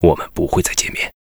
文件 文件历史 文件用途 全域文件用途 Bk2_fw_02.ogg （Ogg Vorbis声音文件，长度1.2秒，128 kbps，文件大小：19 KB） 源地址:游戏语音 文件历史 点击某个日期/时间查看对应时刻的文件。